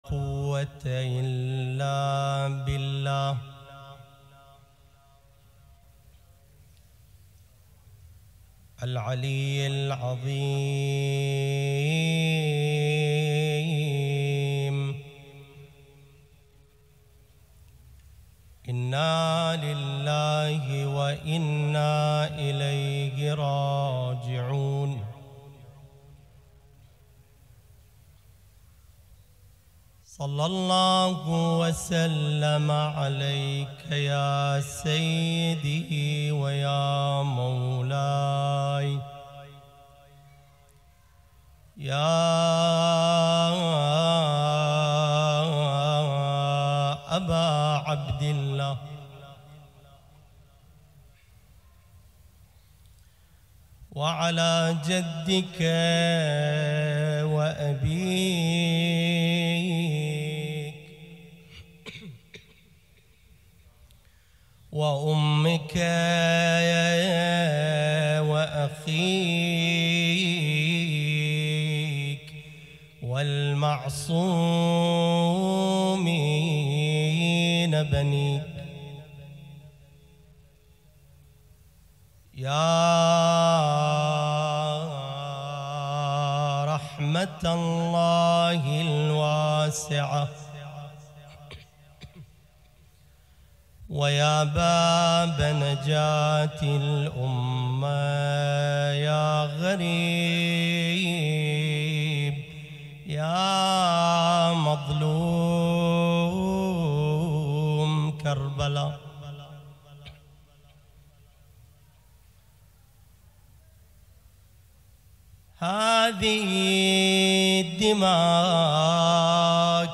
المجلس الحسيني ليلة 5 رمضان 1440هـ
محاضرة